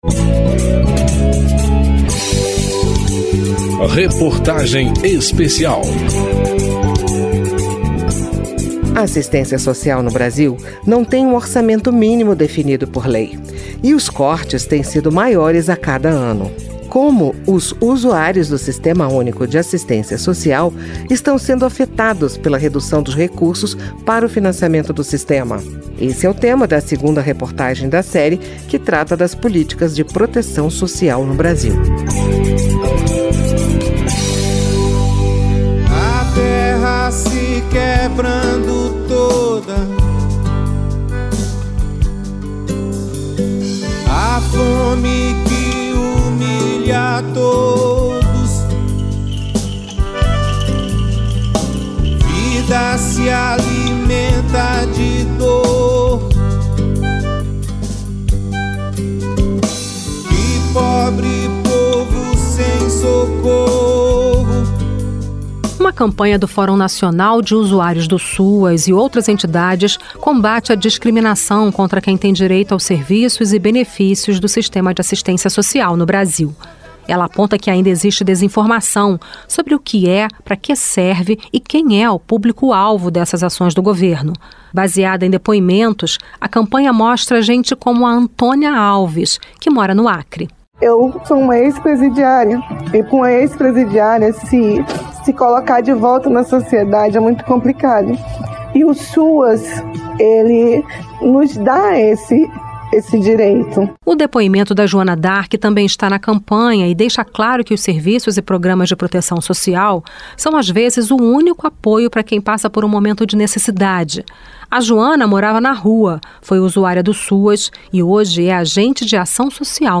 Reportagem Especial
Entrevistas nesse capítulo